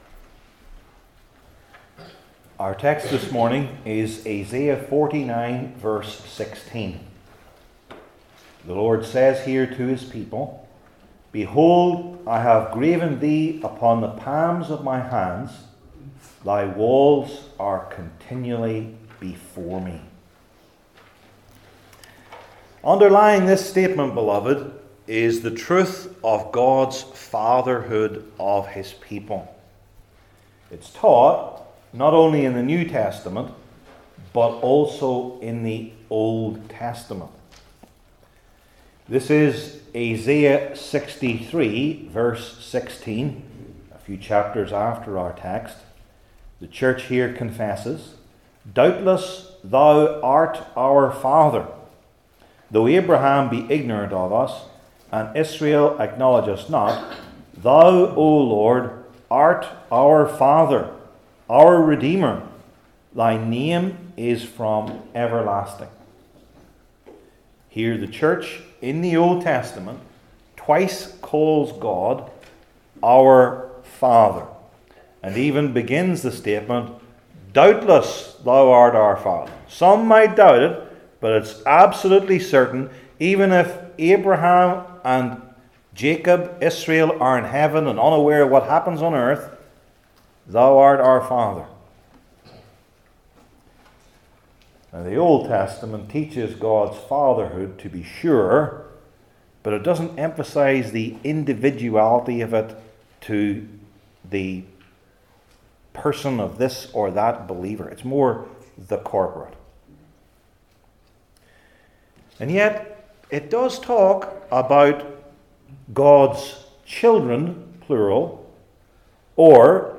Old Testament Individual Sermons I. The Meaning II.